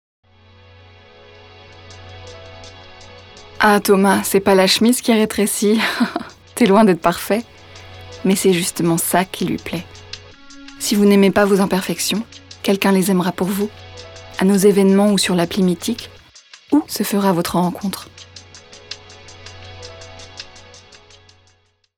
pub 1
Voix off
6 - 40 ans - Soprano